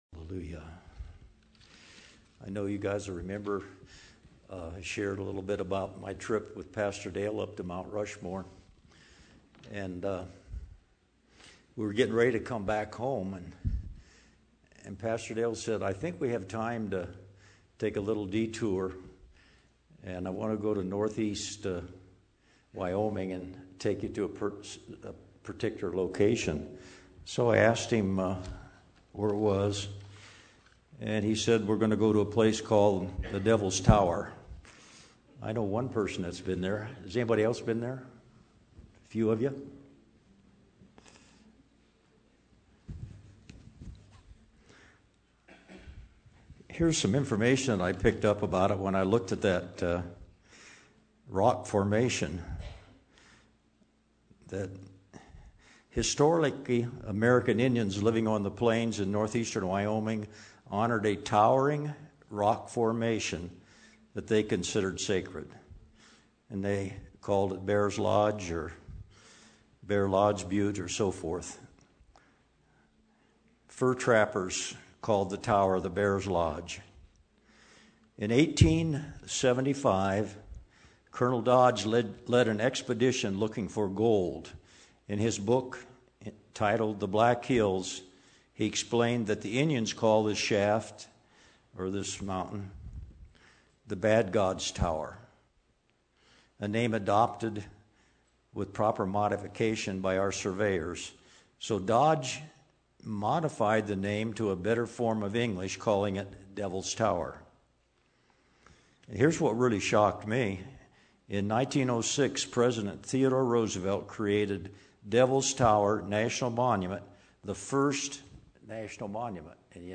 A message from the series "Messages."
Testimonies, teachings, sharing.